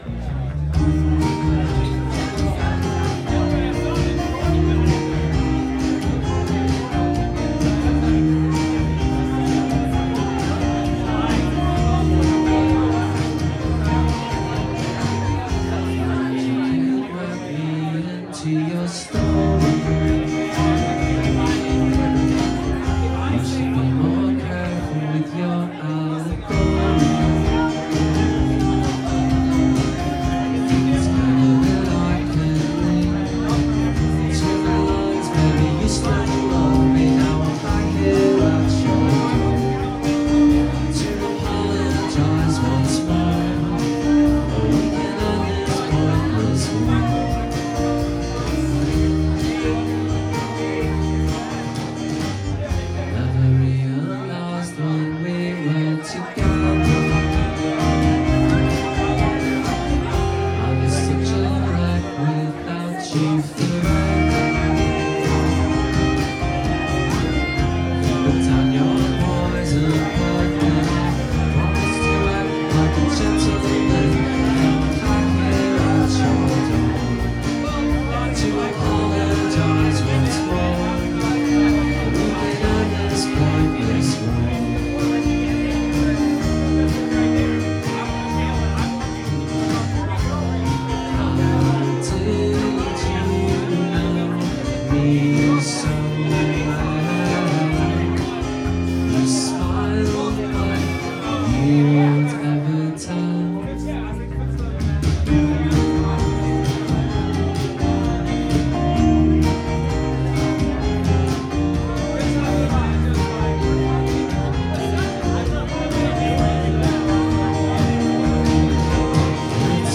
Sunset Tavern – Seattle, WA